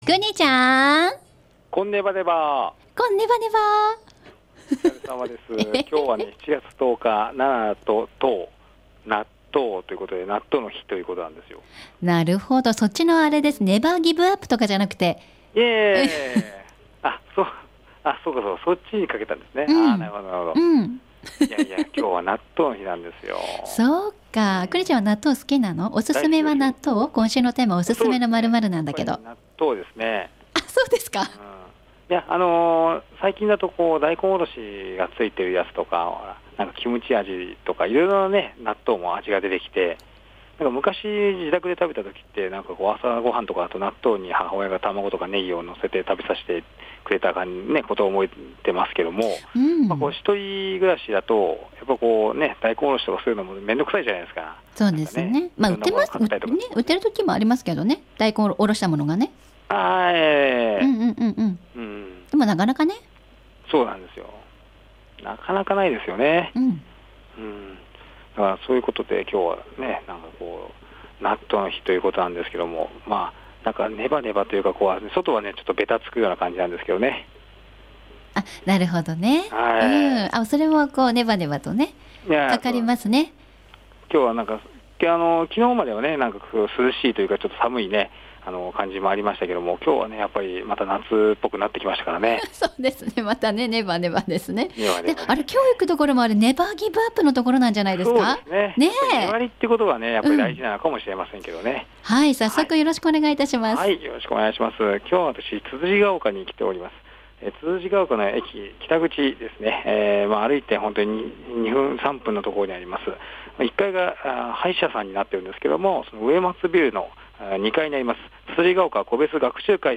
午後のカフェテリア 街角レポート
つつじヶ丘個別学習会にお邪魔しました。